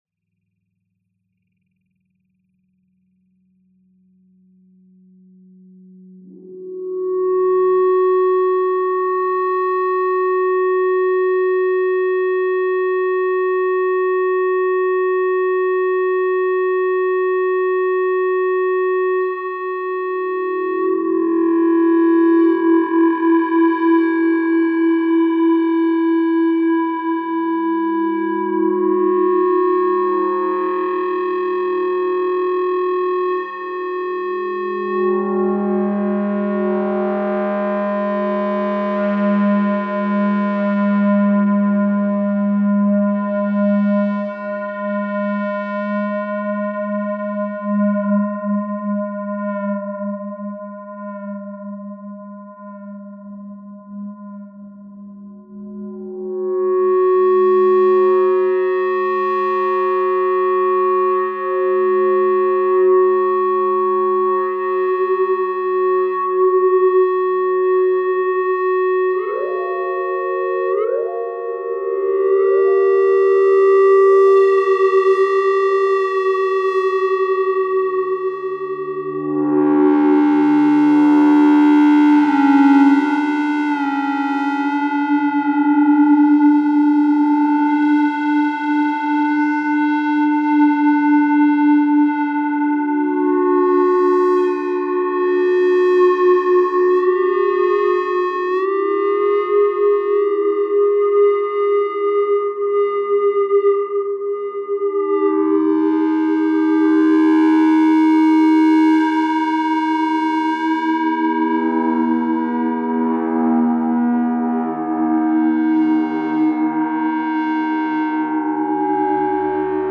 Most of it could probably be done with analog instruments and equipment, but I promise I'll incorporate it into something more digital-sounding at some point.
feedbackdrones.mp3